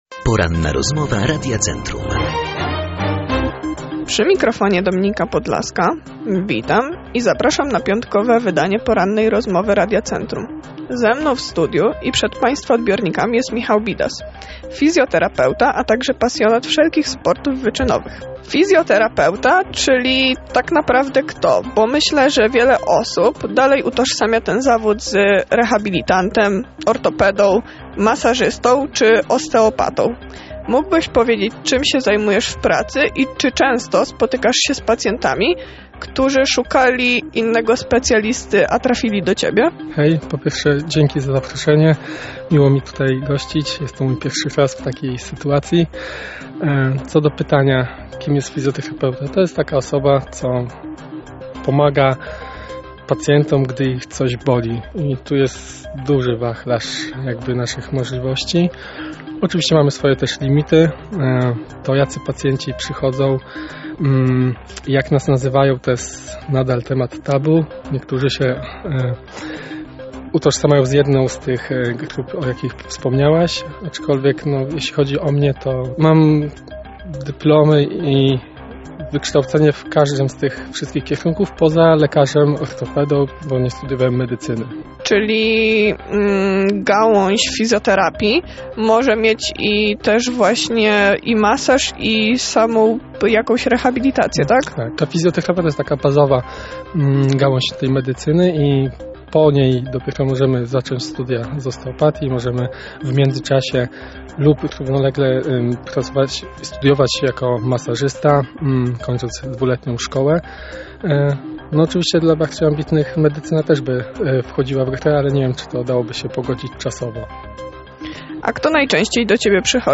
ROZMOWA-3.mp3